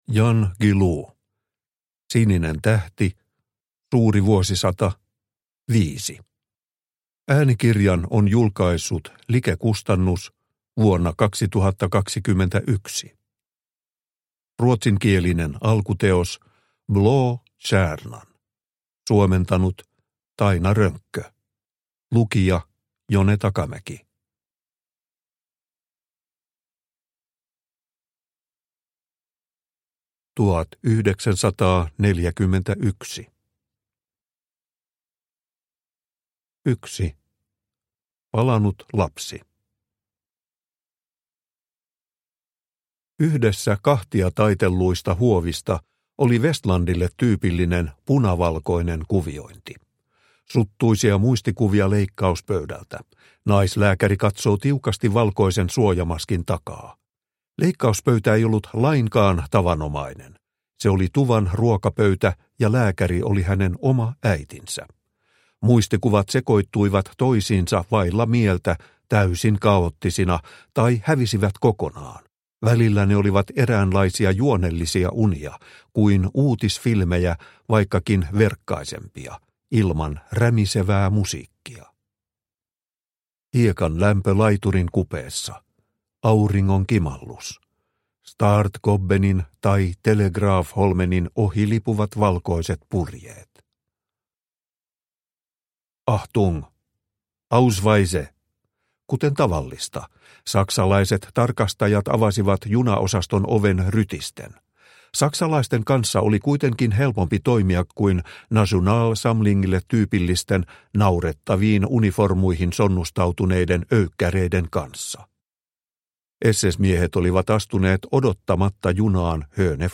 Sininen tähti – Ljudbok – Laddas ner